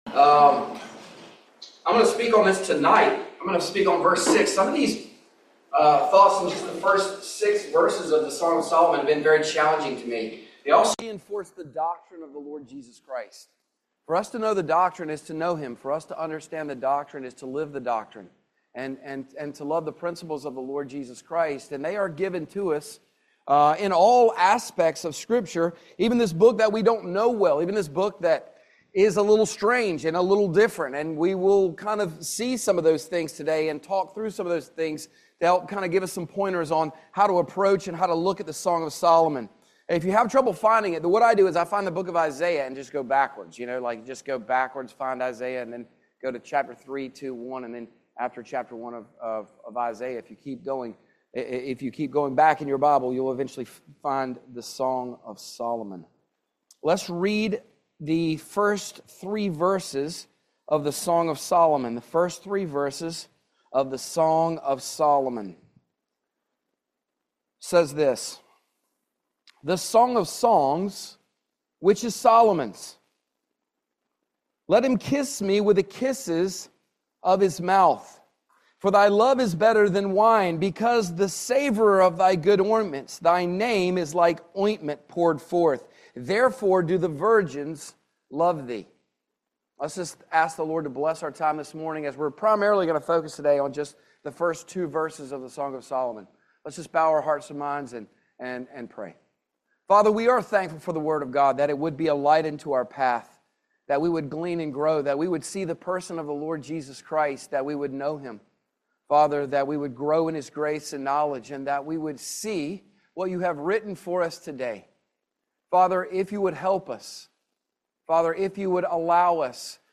Song of Solomon 1:1-2 Service Type: Family Bible Hour Five concepts to interpret in the Song of Solomon.